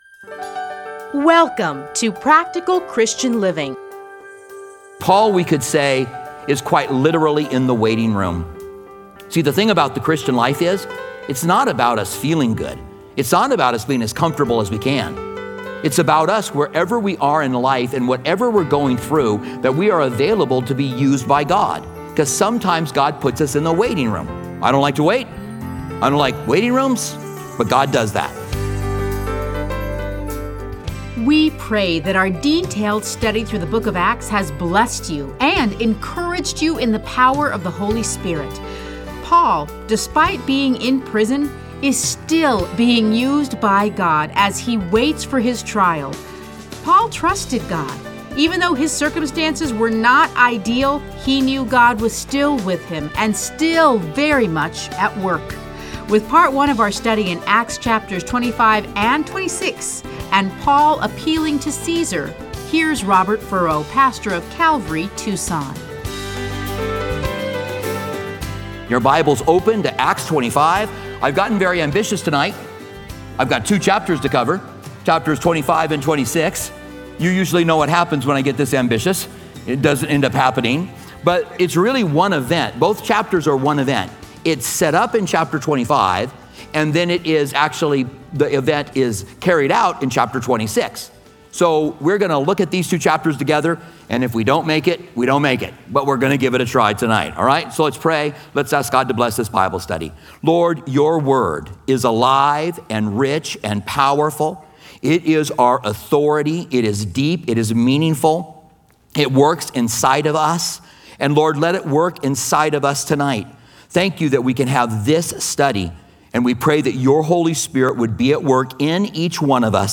Listen to a teaching from Acts 25-26.